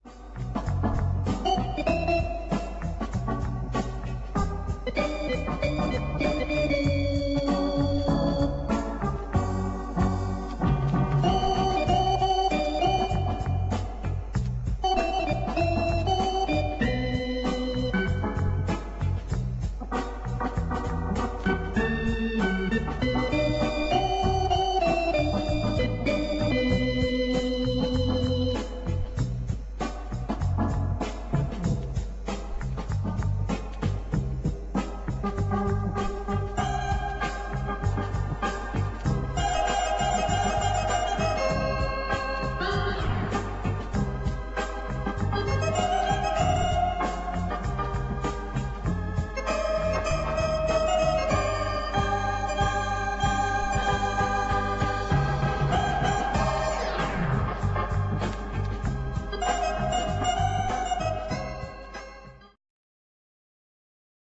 On pourrait dire que ca peut groover quand ça veut.
c'est de l'hammond.